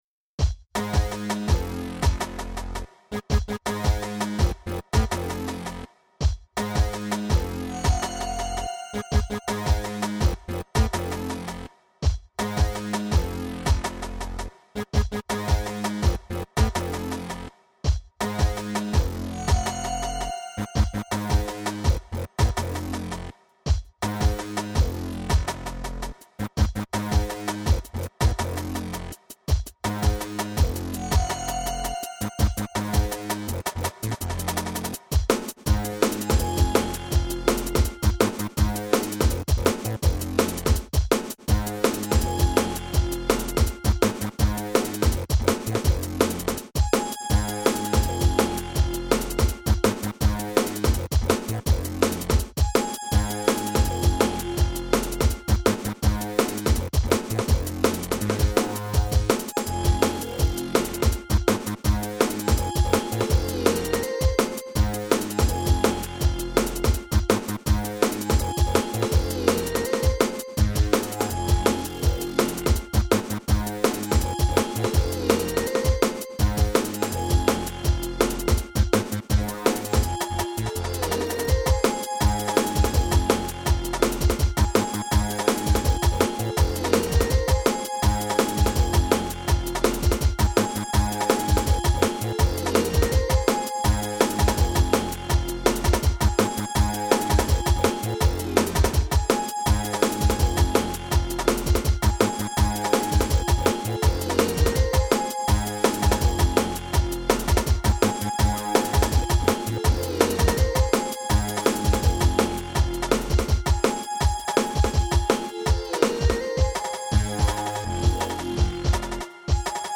-HOMEMADE DRUM AND BASS